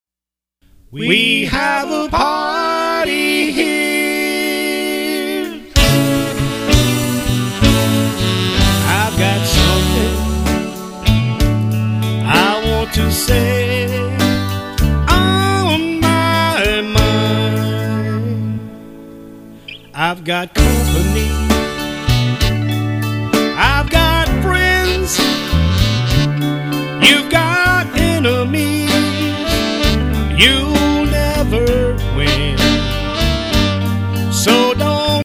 Genre: Blues/R&B.